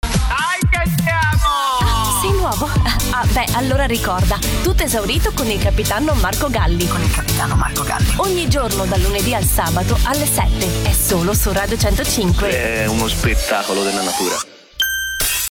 Radiobildgebung
Sie werden meine Stimme aufrichtig, freundlich, authentisch, hell, emotional, institutionell, frisch und energisch finden.
- Professionelle Gesangskabine